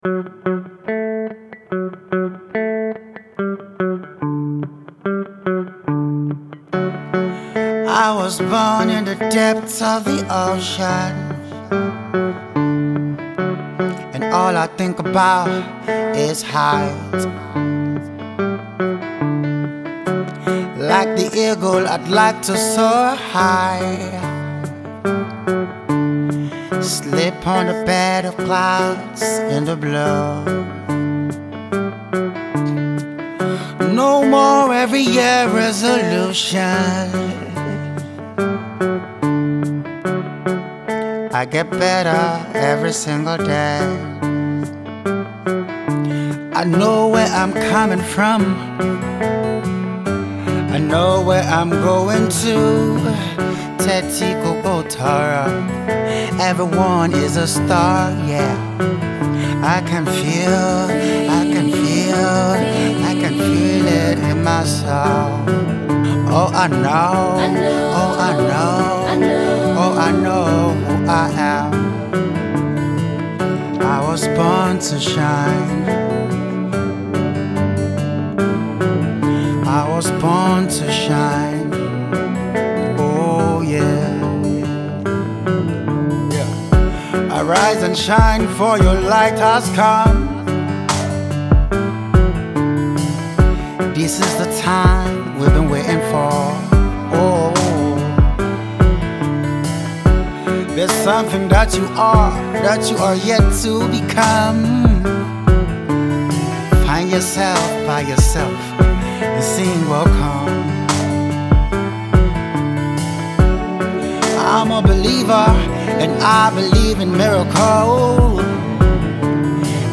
A Soulful Bluesy Ballad
Its a Lovely Ballad